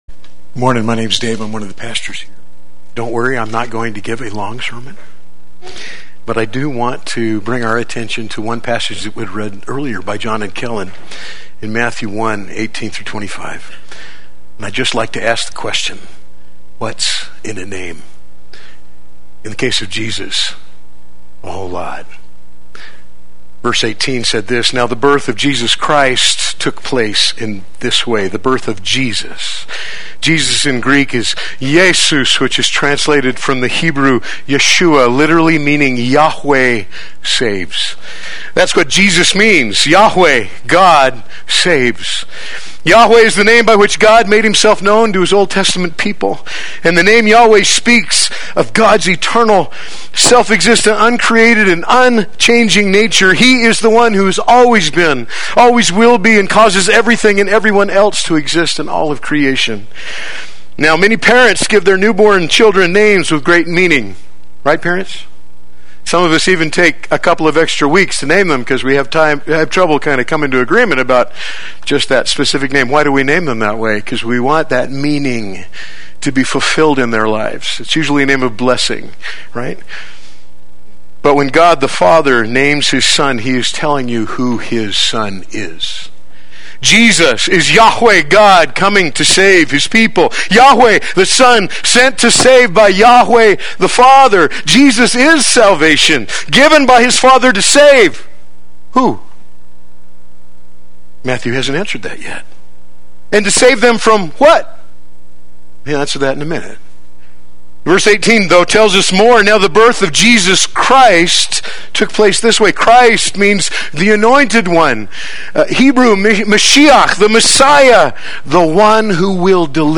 Play Sermon Get HCF Teaching Automatically.
His Name is Jesus Sunday Worship